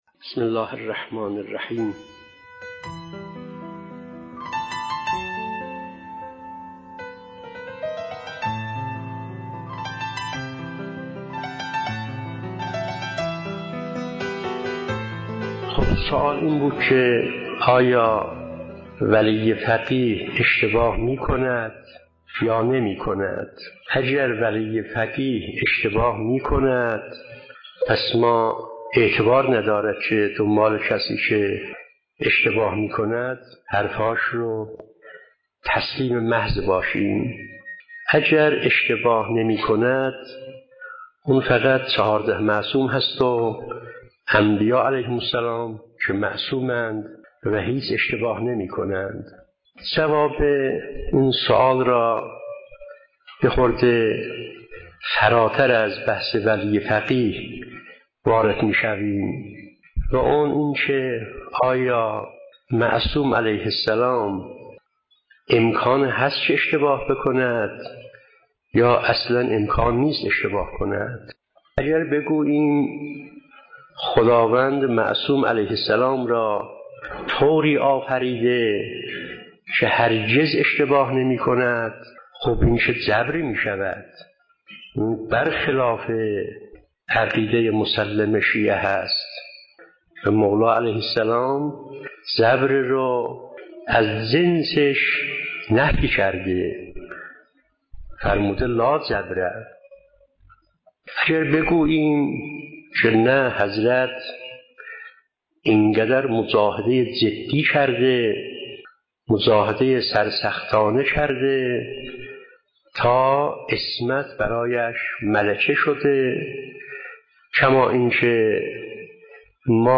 پادپخش ولایت فقیه جلسه ششم با سخنرانی